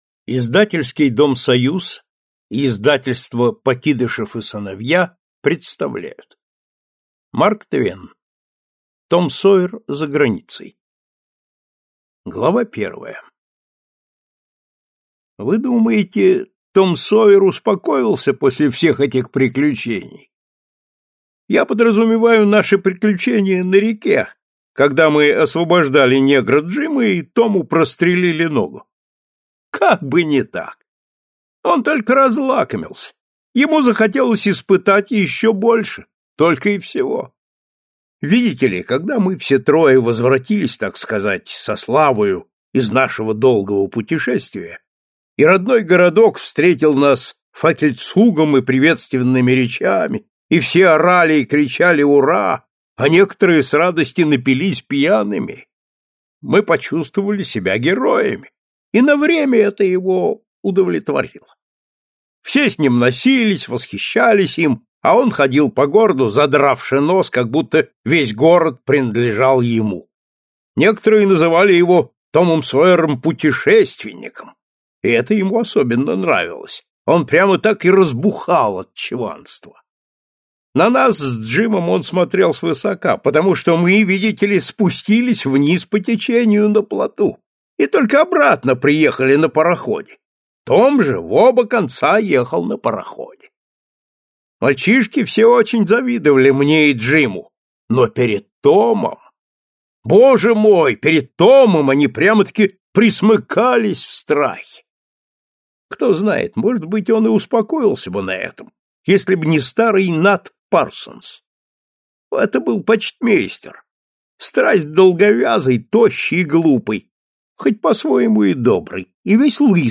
Аудиокнига Том Сойер за границей | Библиотека аудиокниг